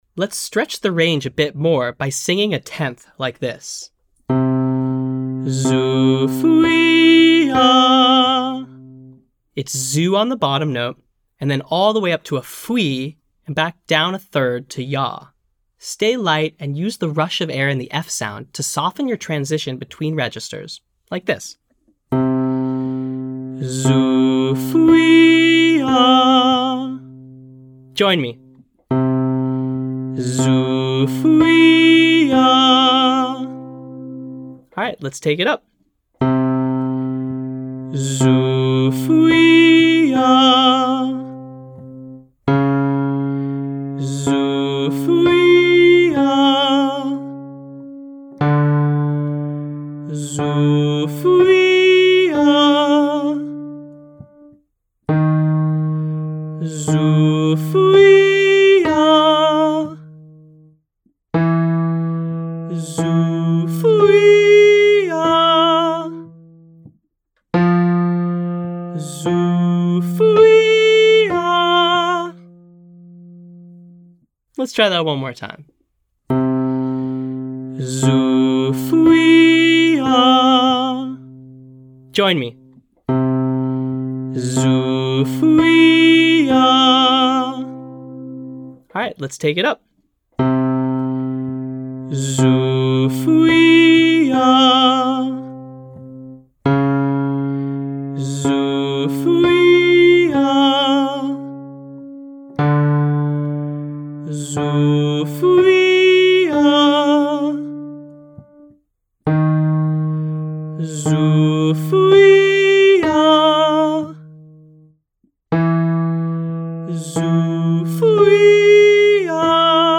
Exercise: Zoo Fwee Yah  C
It’s Zoo on the bottom note and then all the way up to Fwee and back down a third to Yah.